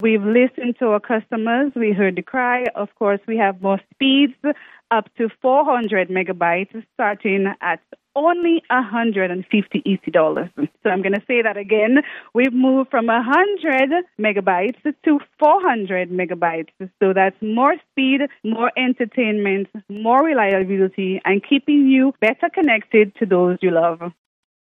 Speaking during a live broadcast on VON Radio in Charlestown on Friday February 23rd, representatives from Digicel announced the rollout of its new Supreme Fiber plans, promising faster speeds, greater reliability and expanded digital opportunities for residents and businesses.